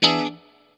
CHORD 7   AF.wav